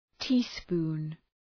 {‘ti:,spu:n}